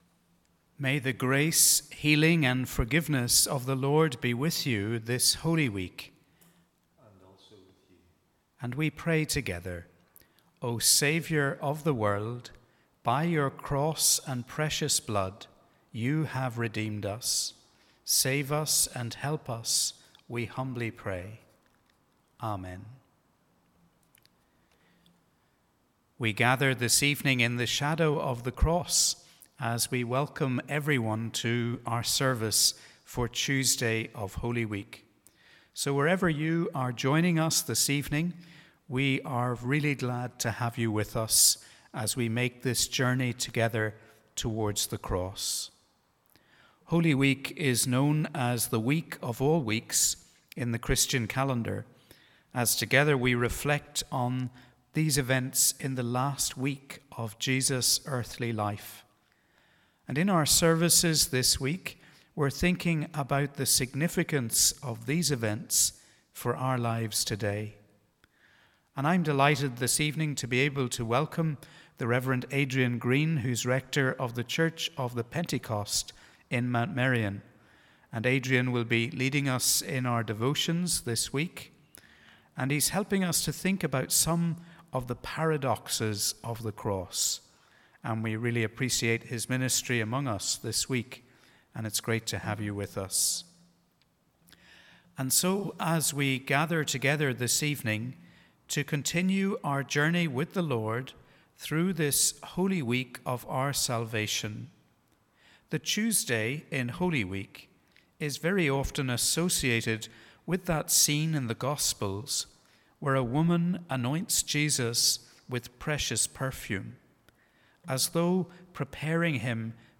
Welcome to our service on this Tuesday in Holy Week.